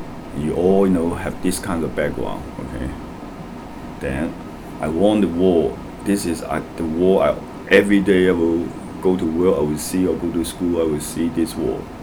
S1 = Hong Kong male S2 = Malaysian female Context: They are talking about painting murals in America.
Intended Words : want the wall Heard as : won the war
This seems to arise mainly because of the omission of the final [l] in the word. S1 pronounces want with the vowel [ɒ] , which is as expected in RP British English.